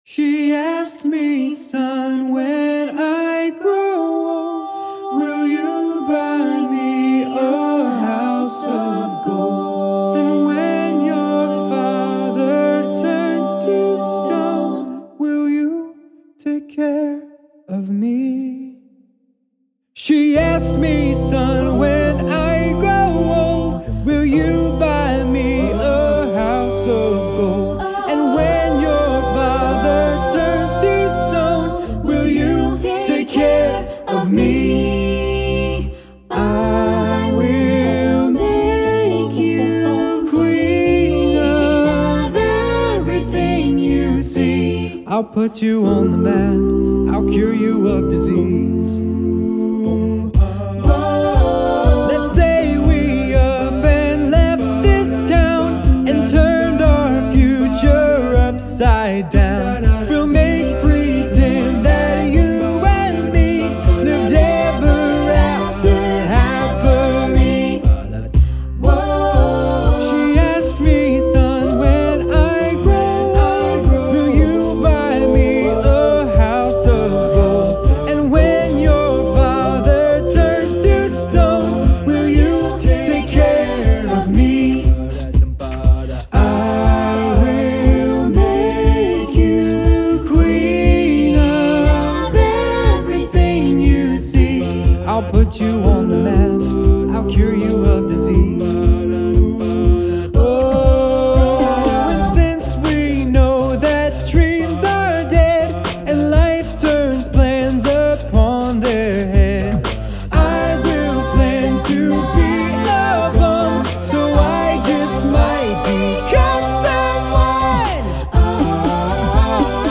Voicing: SSATB a cappella